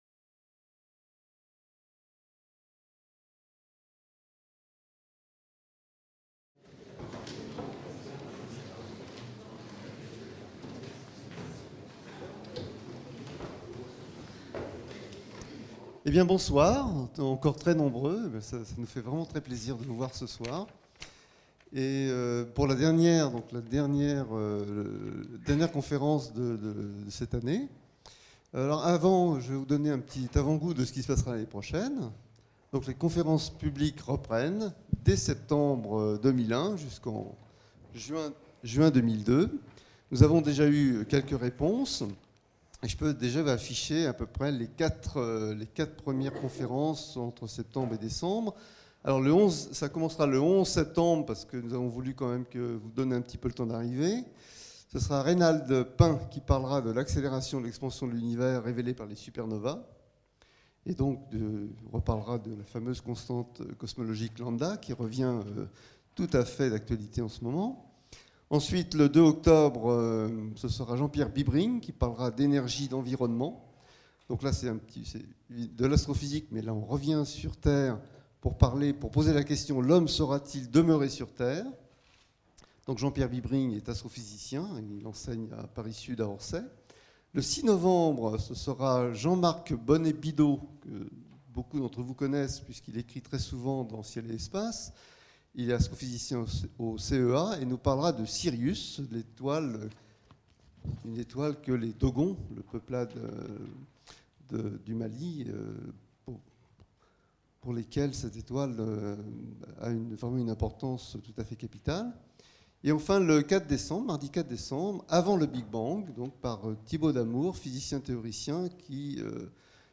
Conférence donnée par Jean-Pierre Luminet, dans le cadre des conférences publiques de l'IAP.